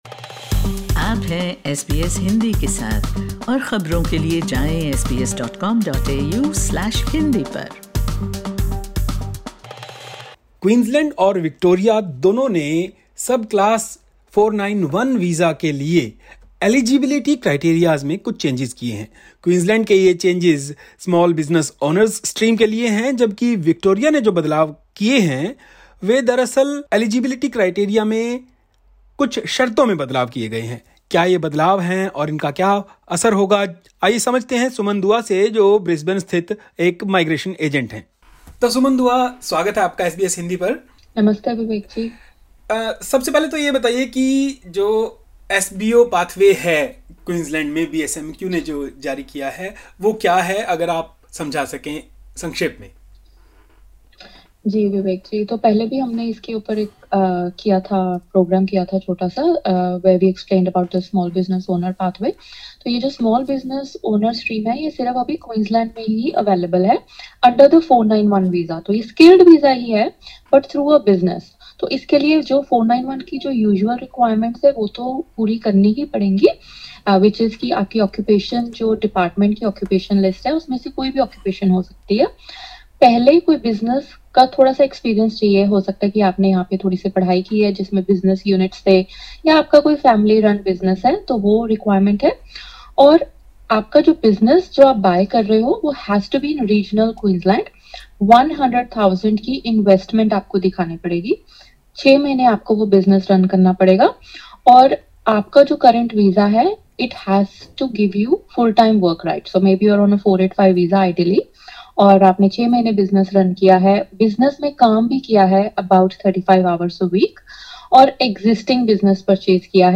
बदलावों के बारे में विस्तार से जानने के लिए सुनिए यह बातचीतः